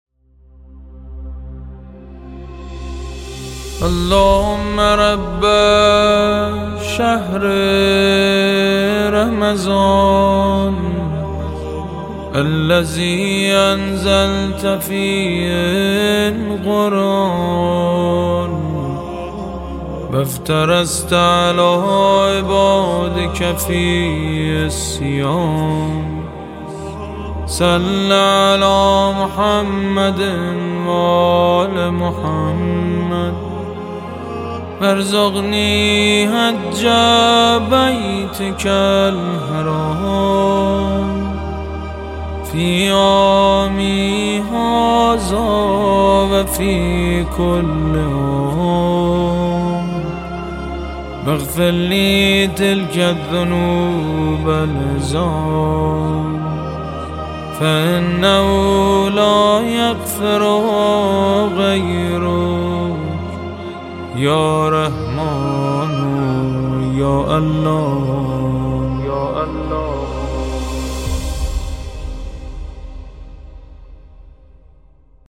نماهنگ «اللهم رب شهر رمضان» با نوای سید مجید بنی فاطمه / ویژه ماه مبارک رمضان (کلیپ، صوت، متن)
به مناسبت حلول ماه مبارک رمضان، مناجات زیبای «اللهم رب شهر رمضان» با نوای دلنشین سید مجید بنی فاطمه تقدیم روزه داران و میهمانان سفره الهی ...